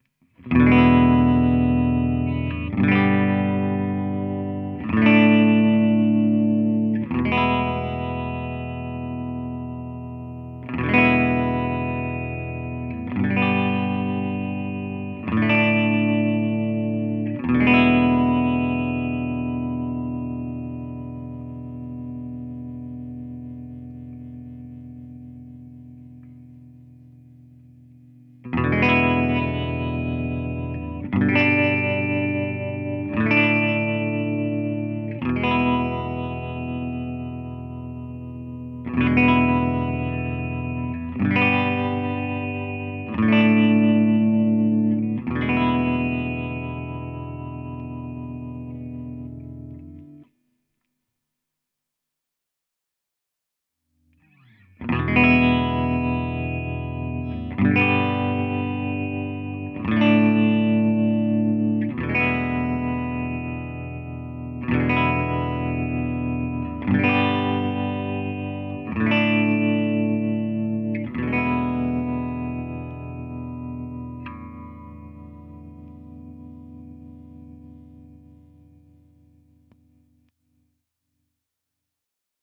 My latest guitar amp project is a stereo amp with vibrato and reverb.
vibratostereo1_r1_session.flac